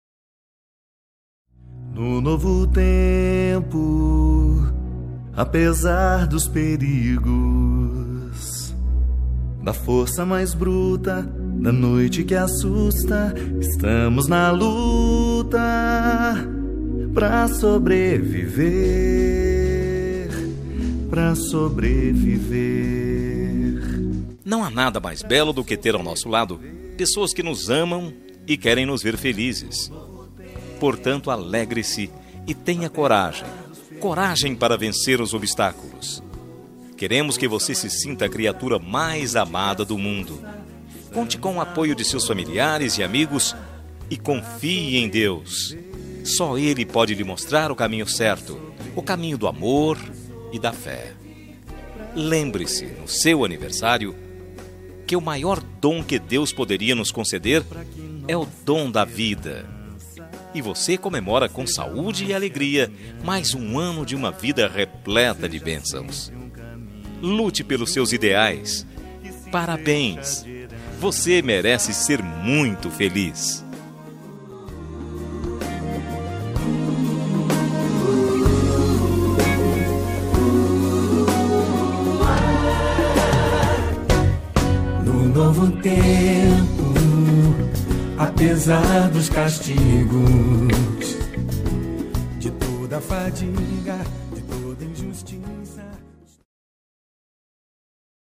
Aniversário Religioso – Voz Masculina – Cód: 34883